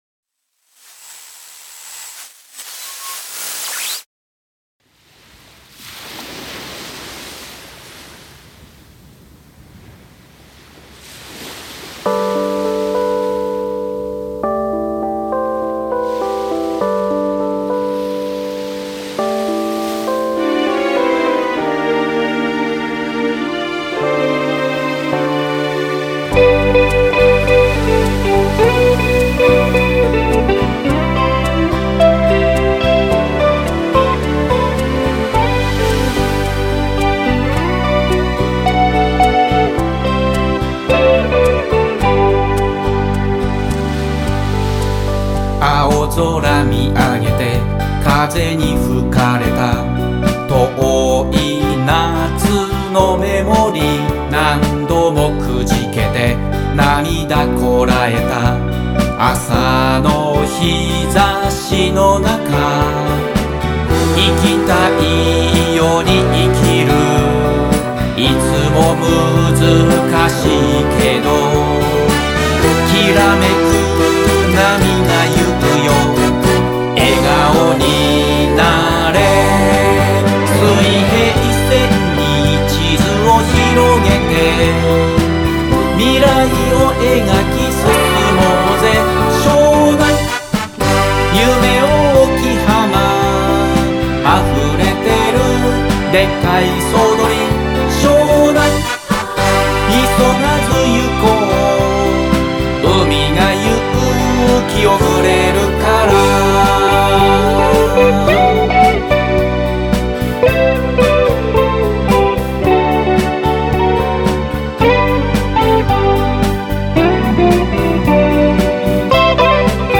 ゴキゲンな"ヨコゆれの世界"100％湘南サウンド保証付きです。